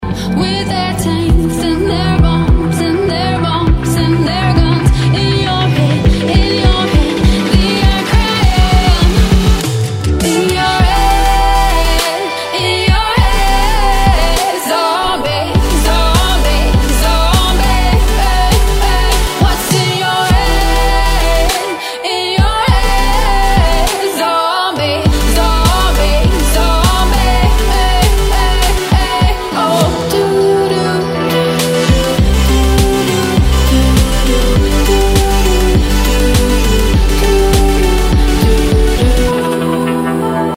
Trap рингтоны